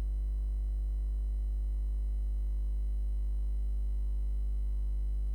Ok so the pluggable usb hub came in the post today and it has got rid of the high pitched humming which is great but now I get a low pitched hum.
That’s got some 1KHz harmonics from the computer and now has added 50Hz harmonics (mains hum) …